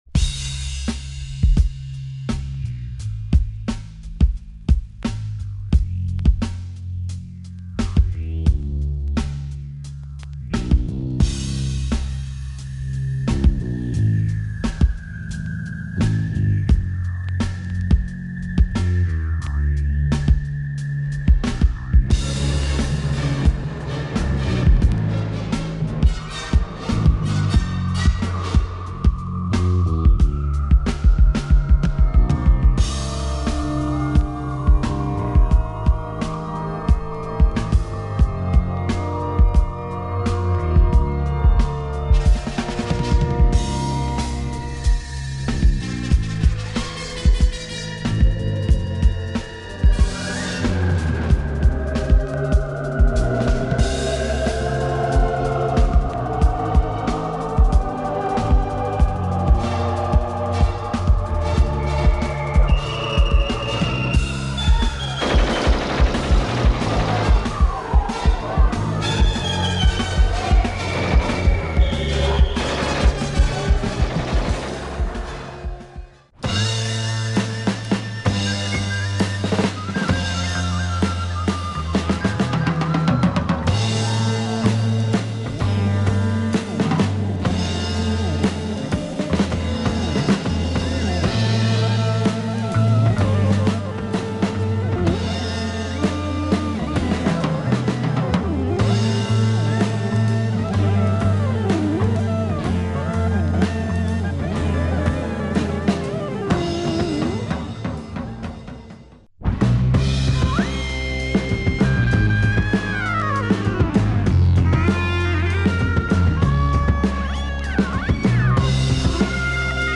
Killer Russian prog funk